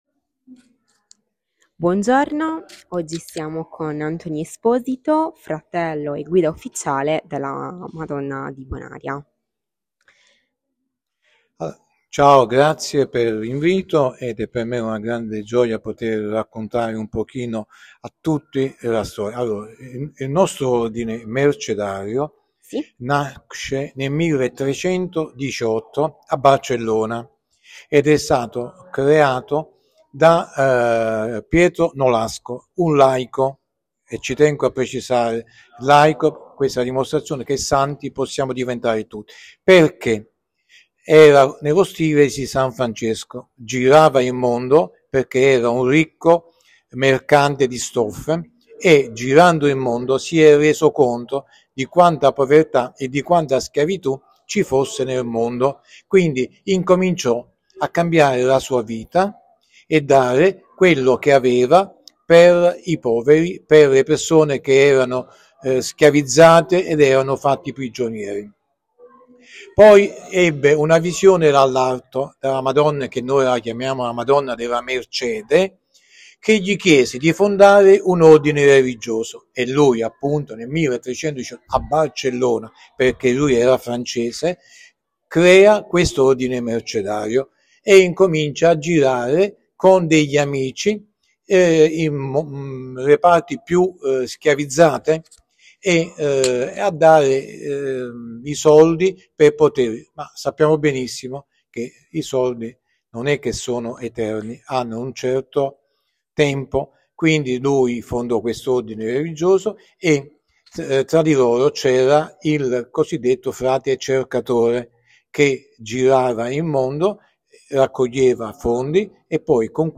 Intervista
Apparecchiatura di registrazione Microfono e cellulare